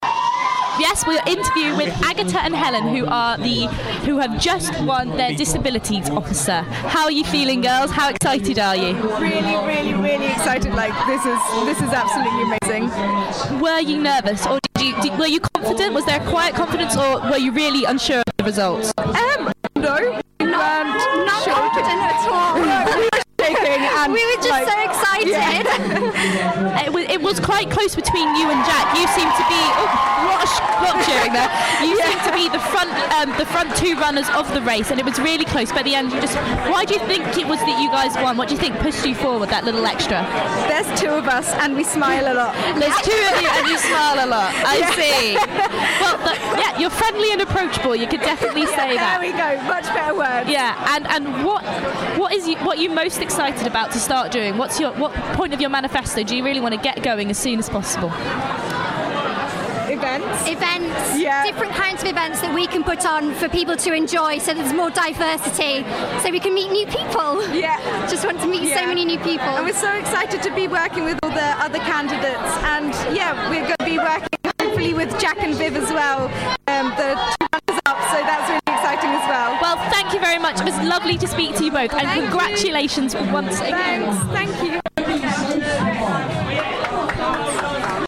Students with Disabilities Officers interview